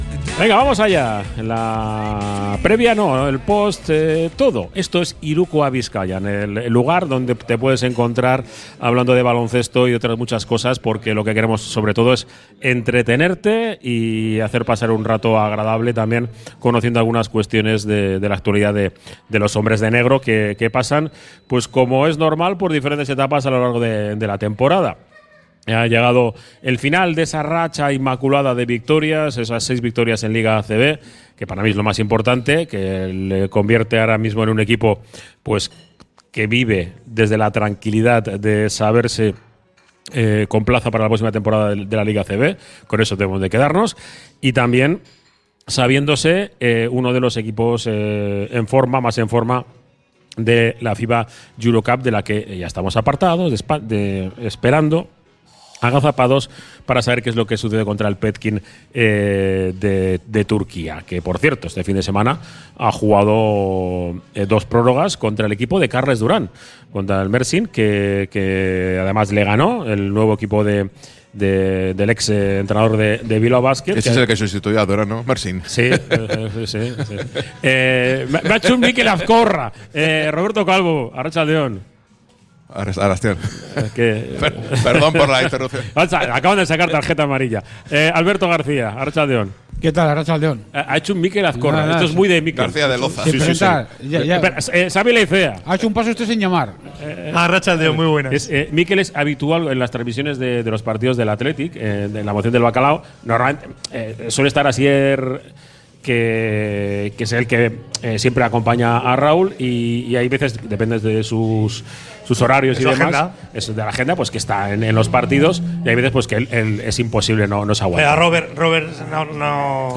Desde el Bar Izar